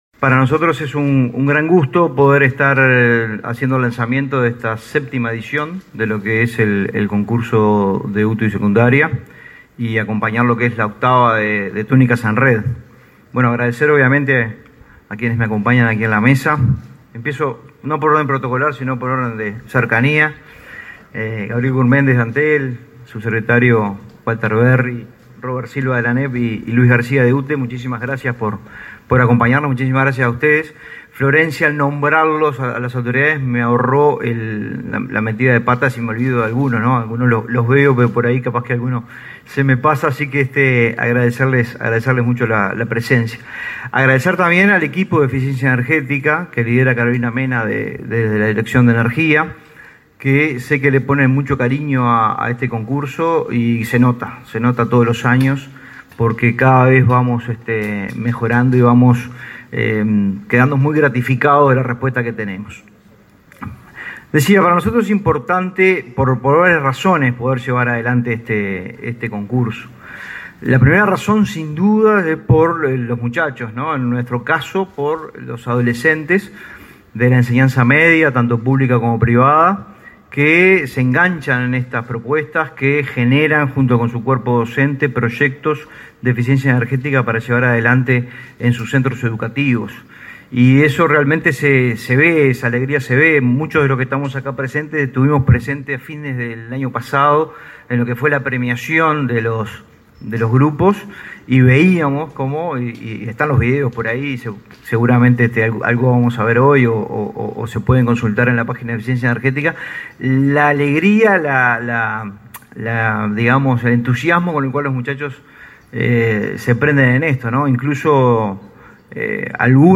Lanzamiento del Concurso de Eficiencia Energética 29/03/2022 Compartir Facebook X Copiar enlace WhatsApp LinkedIn Este martes 29 , se realizó en Montevideo el lanzamiento del Concurso de Eficiencia Energética, para alumnos de UTU y Secundaria.